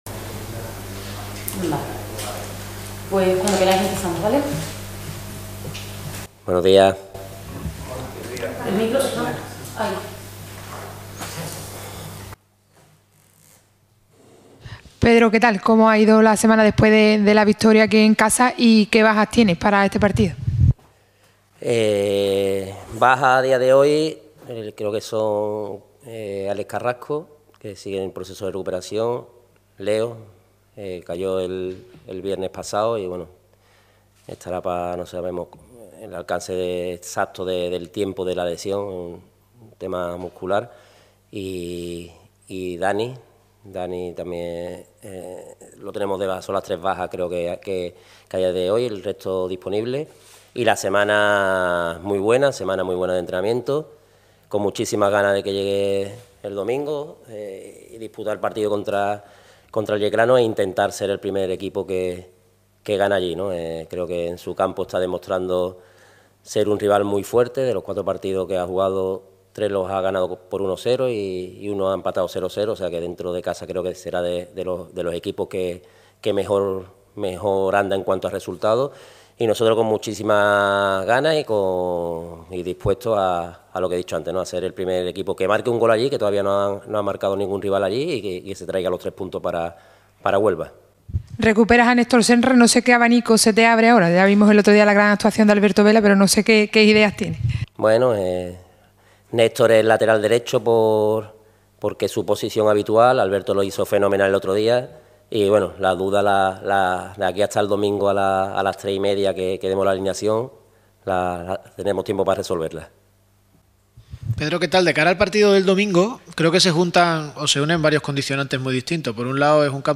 ofreció la rueda de prensa previa al partido del domingo ante el Yeclano.